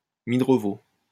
Midrevaux (French pronunciation: [midʁəvo]